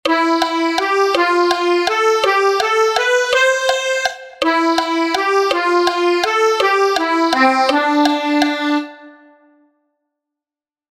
Humpty_ternaria.mp3